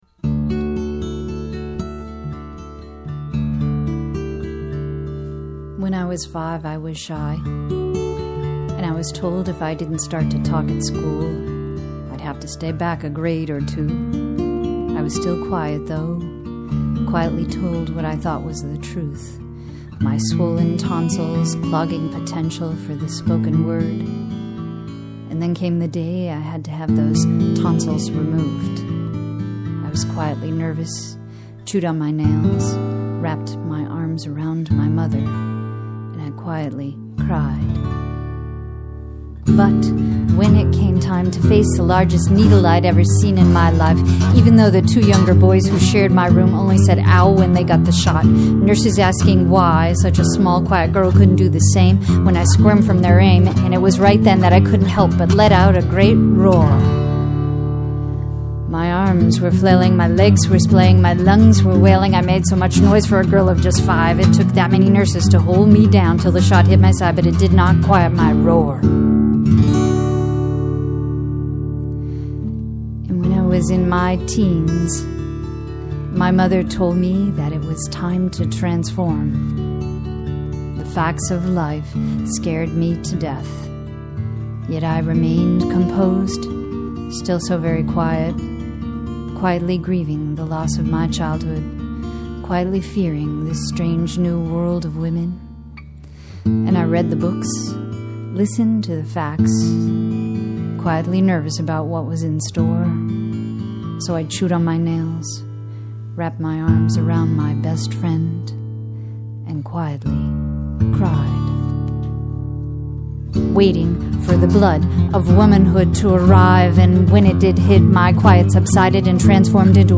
love the accompanying guitar, as well!